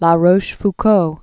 (lä rōsh-f-kō, -rôsh-), Duc François de 1613-1680.